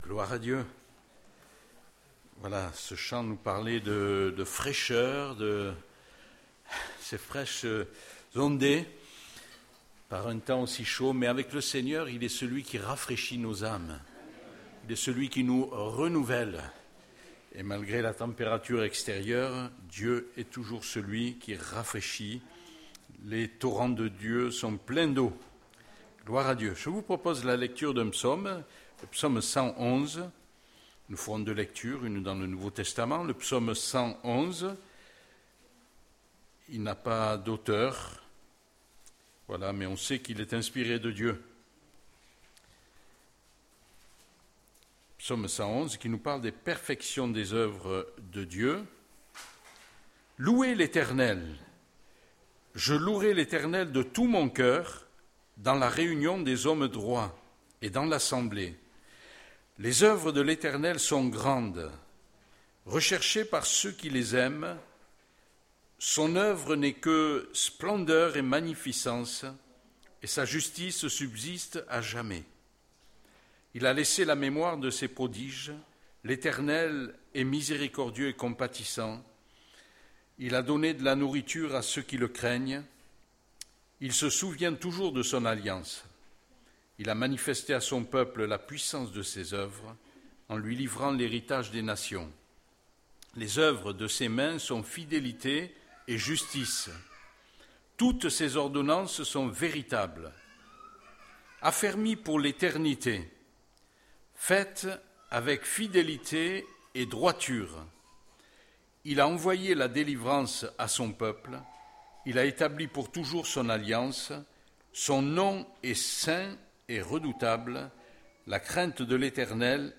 Texte biblique: Psaume 111:1-10 & 1Pierre 2:6;10 | Prédicateur